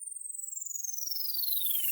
Riser